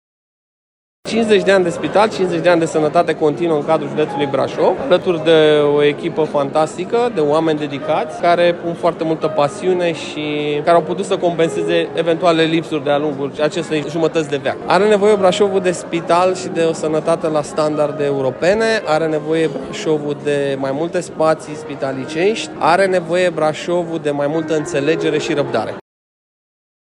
Cu acest prilej, reprezentanții unității medicale au organizat, astăzi, un eveniment aniversar la care participă foști și actuali angajați, dar și oficialități care s-au implicat, de-a lungul timpului, pentru dezvoltarea spitalului brașovean.